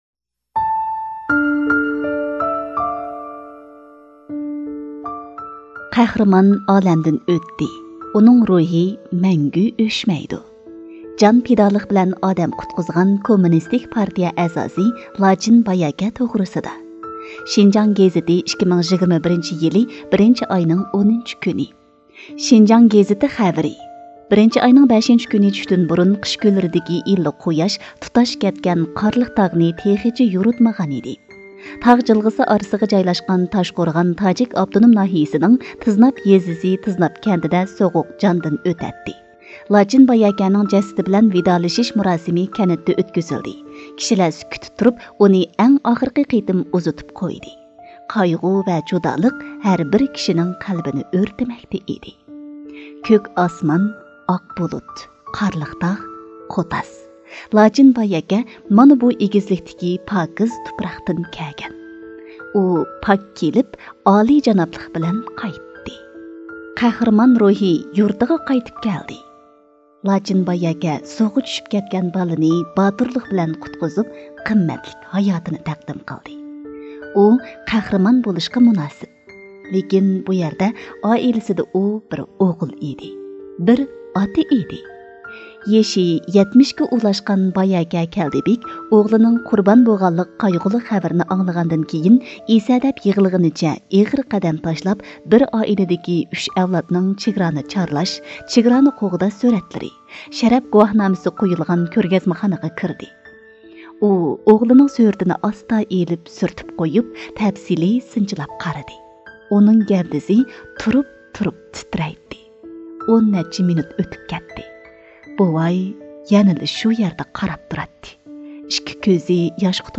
HOT-电台主播